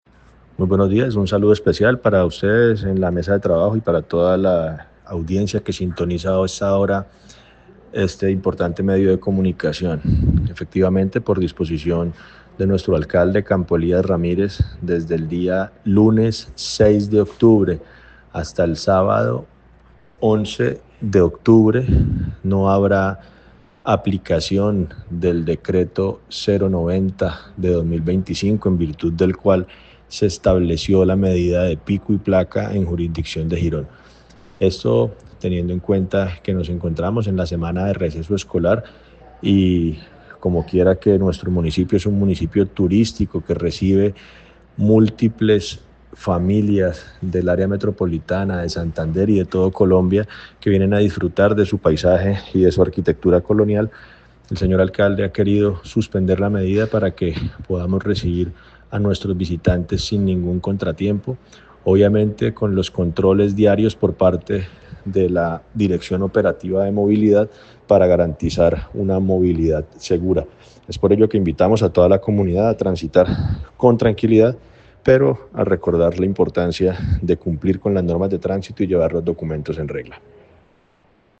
El secretario de Tránsito y Movilidad de Girón, Juan José Gómez, explicó que la medida busca facilitar la movilidad y el acceso de las familias que visitan el municipio durante este periodo vacacional:
Juan José Gómez, secretario de Tránsito de Girón.mp3